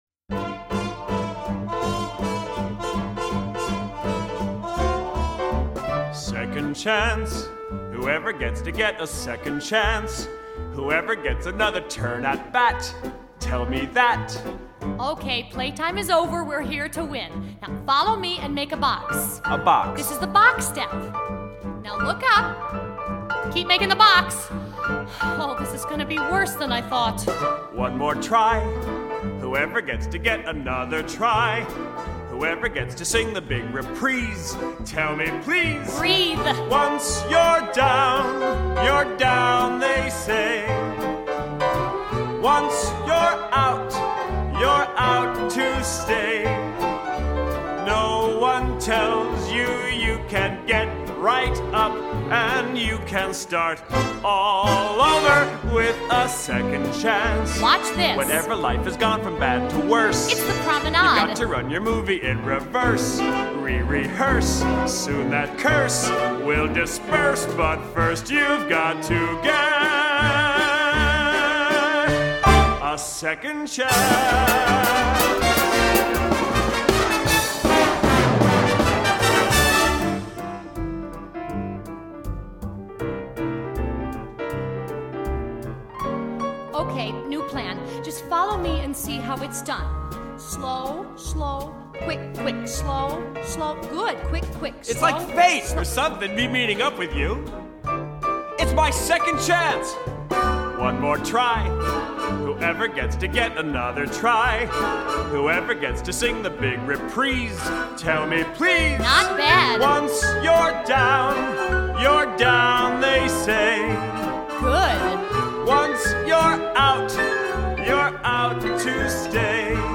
Original Broadway Cast
Genre: Musical